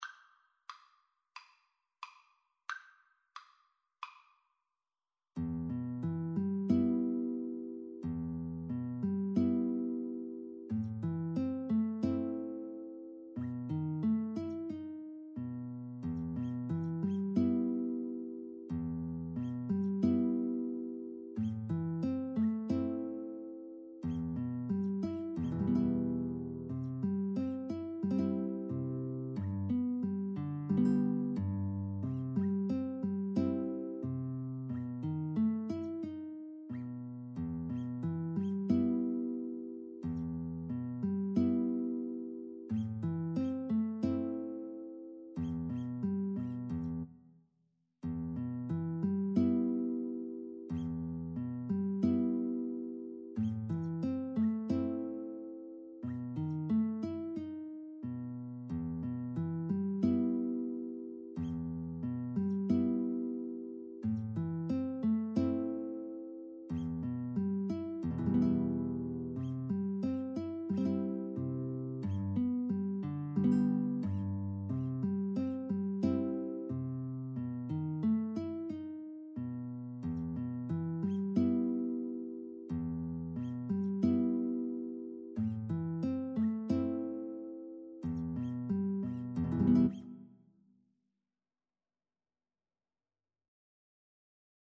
Andante = c. 90